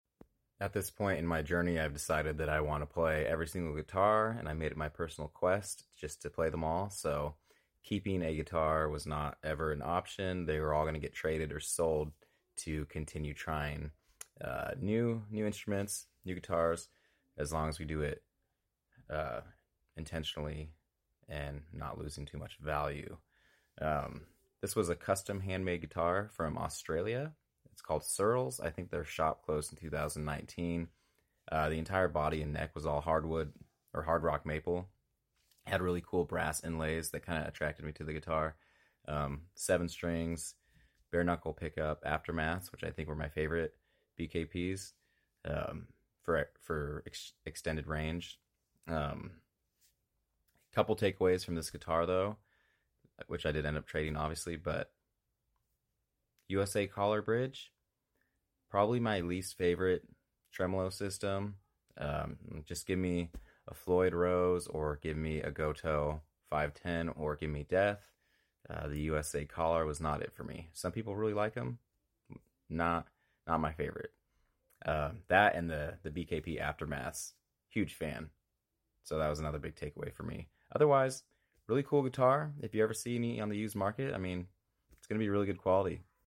Handmade Australian guitar Searls.